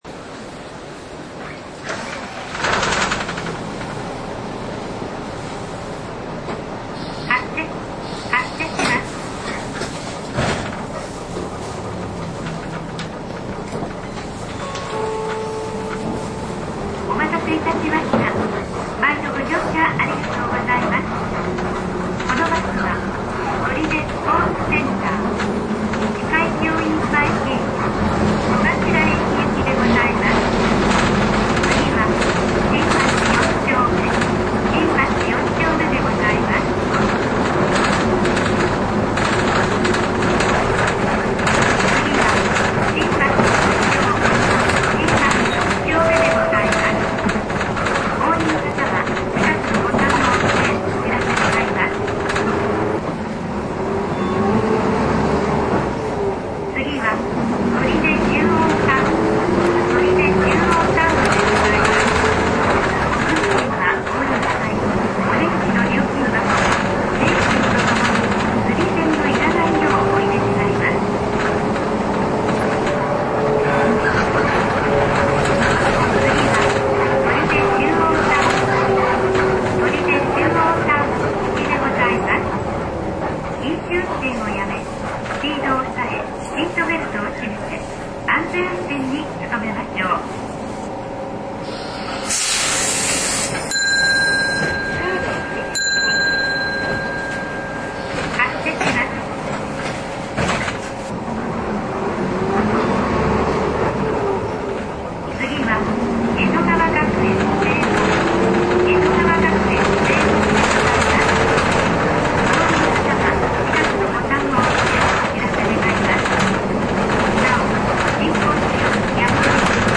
走行音と車内放送で振り返る
車種：左写真のバスで録音しました。
走行音＋全車内放送音